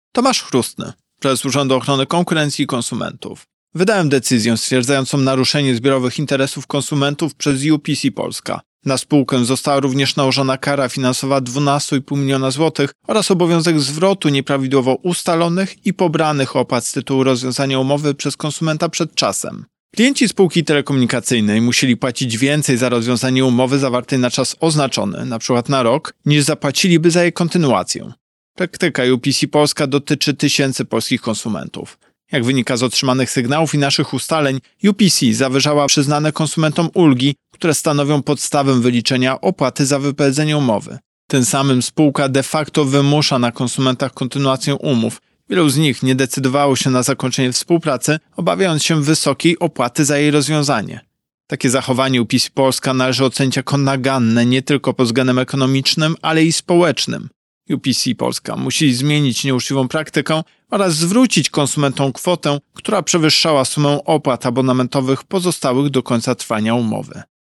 Pobierz wypowiedź Prezesa UOKiK Tomasza Chróstnego Sygnały, które dotarły do UOKiK, dotyczyły przede wszystkim bardzo wysokich opłat naliczanych przez UPC.